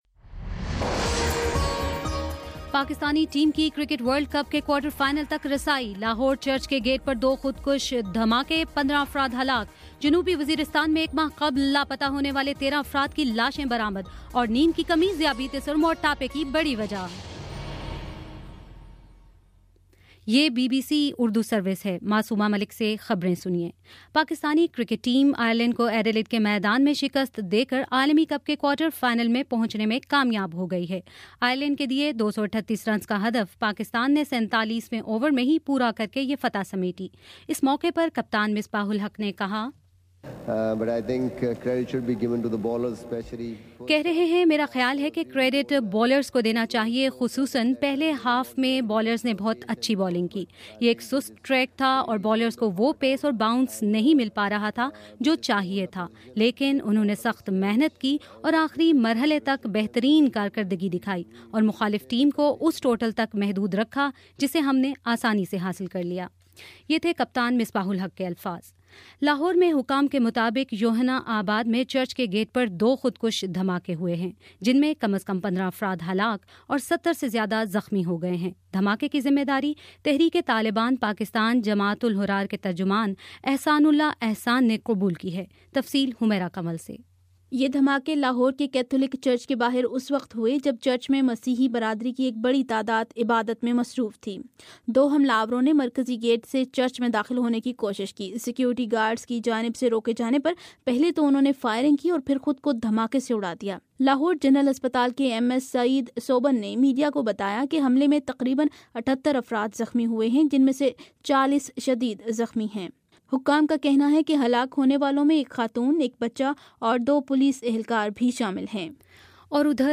مارچ 15: شام پانچ بجے کا نیوز بُلیٹن